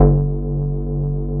SCUD BASS 2.wav